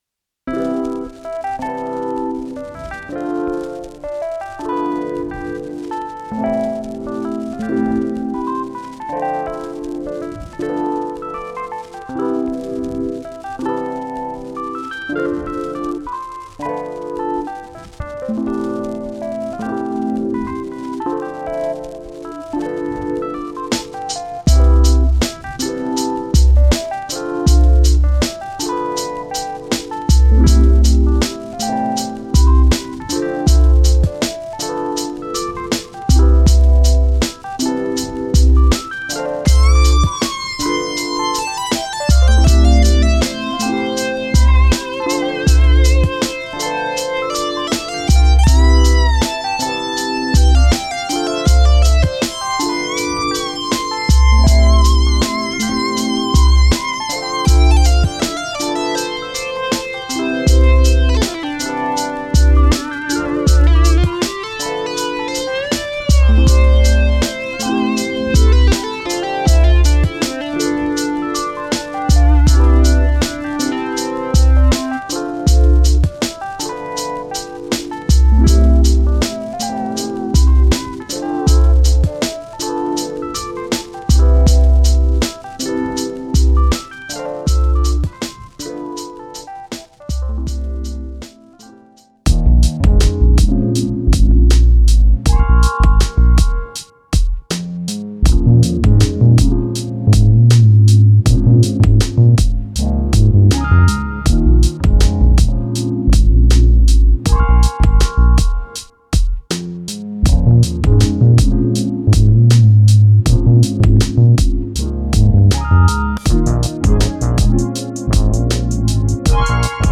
LO-FI Chill Jazzy Beats
A LUSCIOUS FUSION OF SMOOTH LO-FI JAZZY BEATS FEATURING ELECTRIC PIANOS, WARM MOOG LEADS, AND A BEAUTIFUL BLEND OF ACOUSTIC INSTRUMENTS AND ANALOG SYNTHS.
This collection captures the soothing vibe of relaxed jazz-infused soundscapes, perfect for creating a relaxing atmosphere.
Additionally, there is a dedicated macro for lo-fi with bit reduction and another with the “woobler tape” effect, giving you even more control over that signature lo-fi vibe.
Includes 64 unique crunchy drum samples that capture the essence of classic lo-fi percussion, featuring kicks, snares, hi-hats, toms/percussions, vinyl noises, and instruments like electric pianos and synth basees